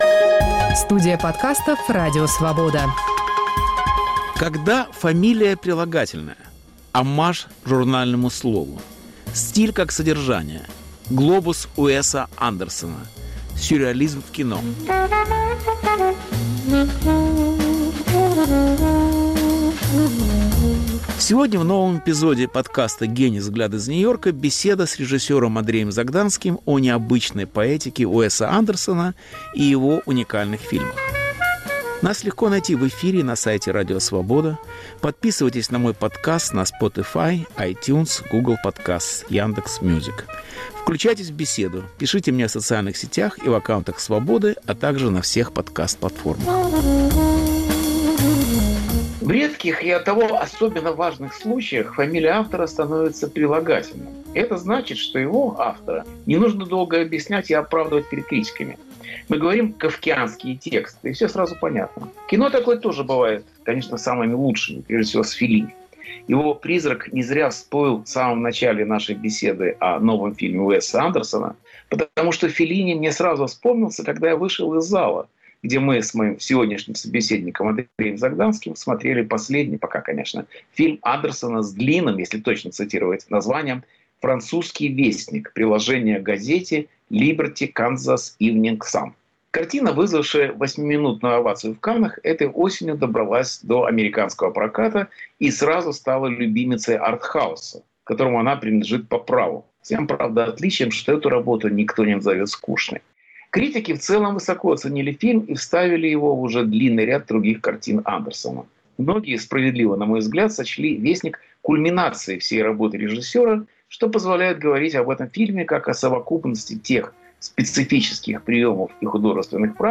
Повтор эфира от 22 ноября 2021 года.